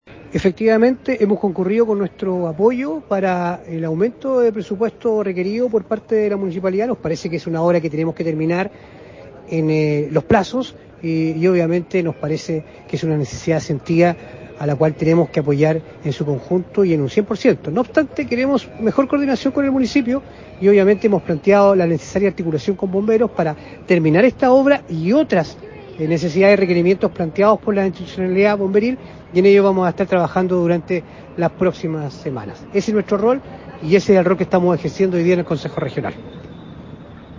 El presidente de la comisión de seguimiento de obras en conflicto del Core, Francisco Reyes Castro, indicó que como cuerpo colegiado están disponibles para fortalecer la coordinación con los municipios de la provincia e instituciones como bomberos.